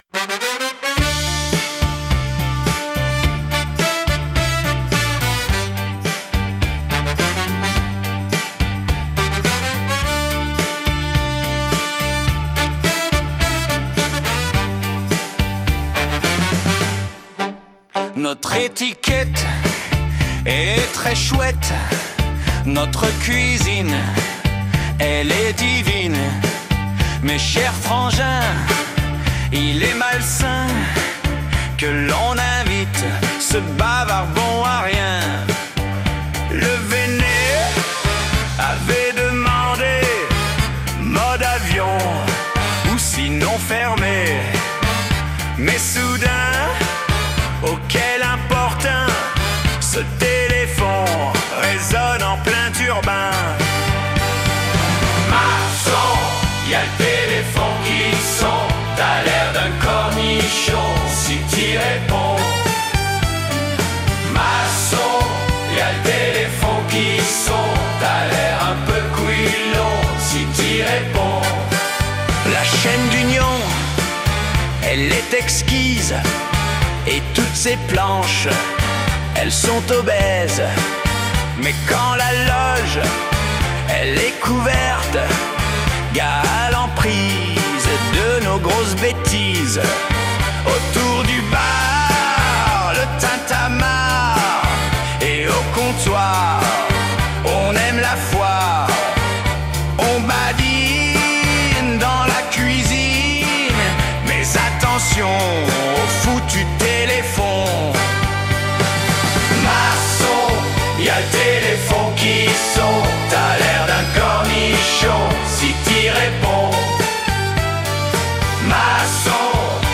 Parodie